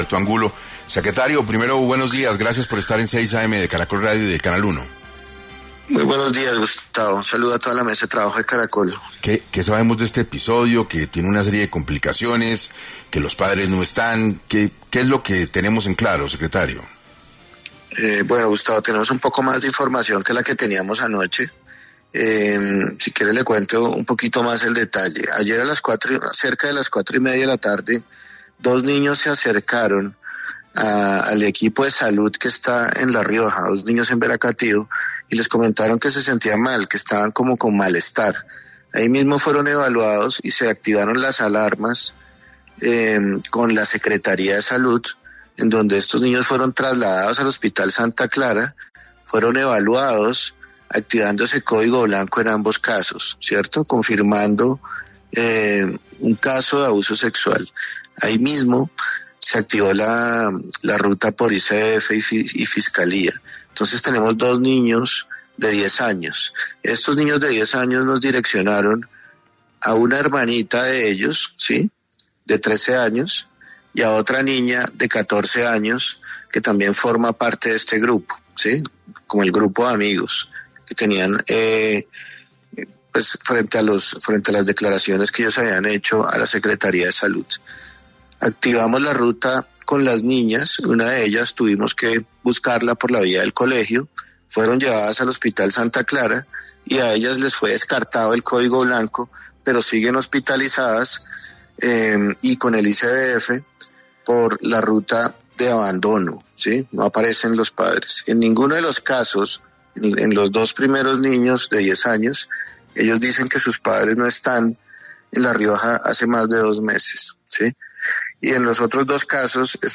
Según indicó el secretario en 6AM de Caracol Radio, al momento no han logrado tener una comunicación con los padres de los menores, quienes se encuentran hace dos meses fuera de Bogotá: